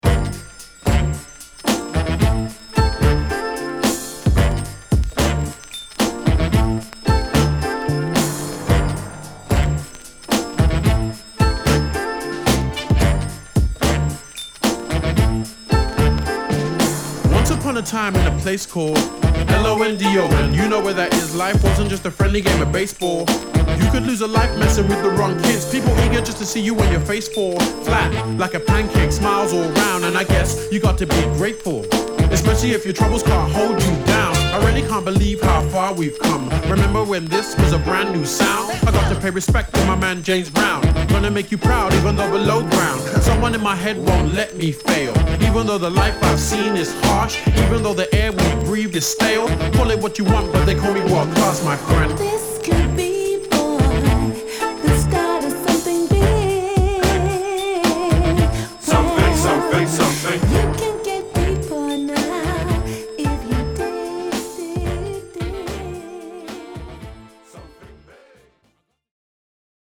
※この盤からの録音ですので「試聴ファイル」にてご確認下さい。